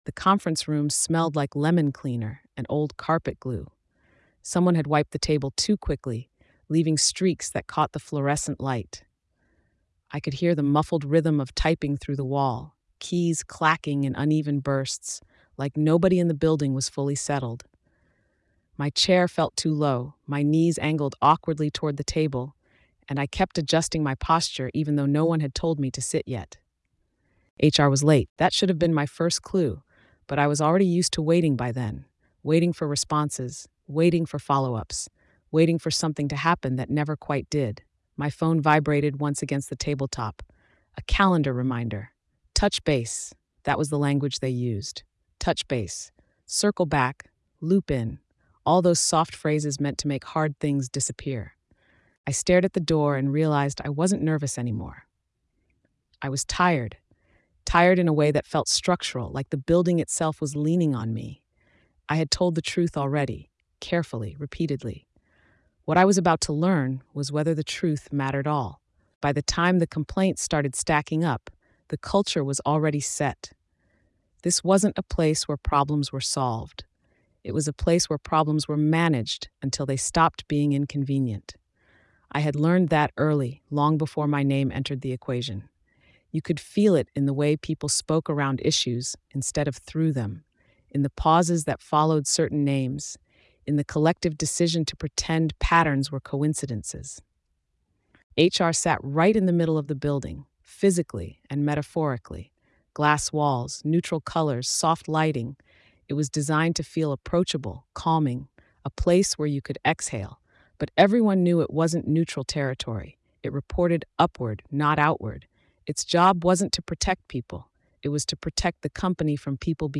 HR Knew and Did Nothing is a tense, first-person account of what happens when workplace complaints are quietly absorbed instead of acted on.